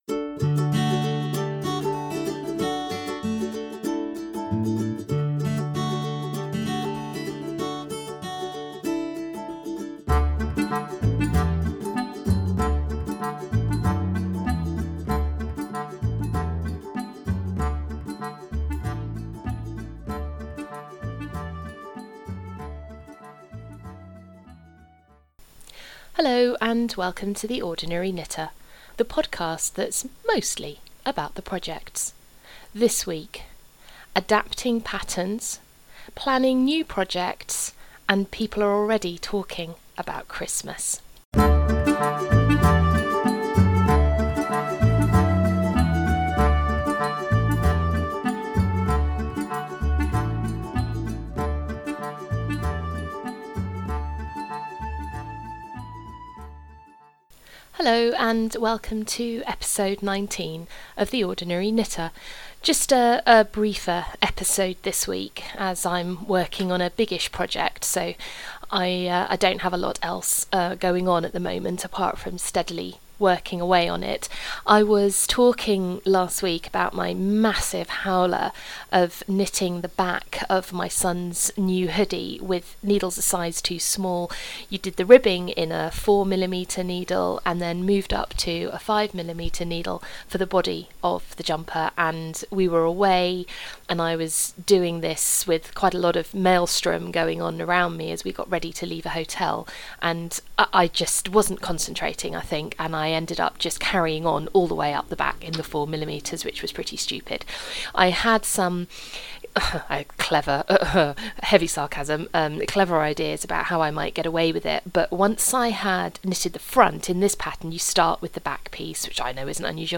I know the music is all over the place in this episode: